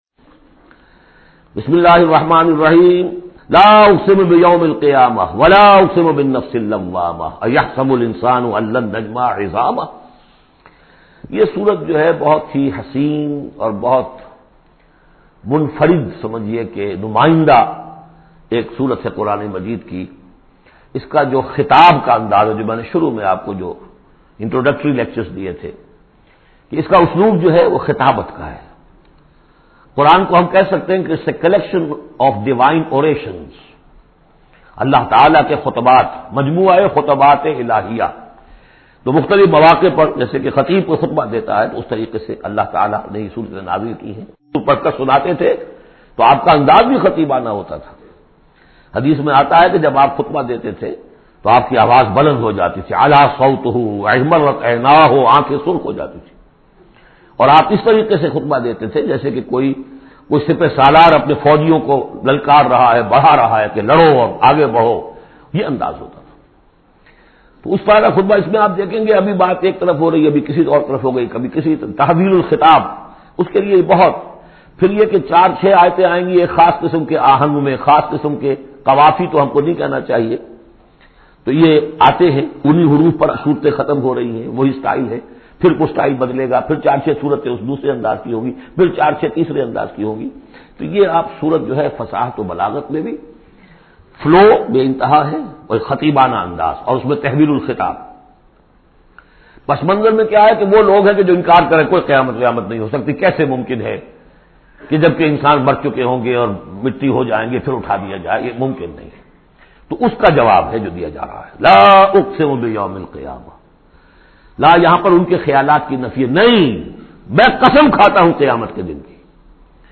Surah Qiyamah Tafseer by Dr Israr Ahmed
Surah al-Qiyamah, listen online mp3 tafseer in urdu by Dr Israr Ahmed.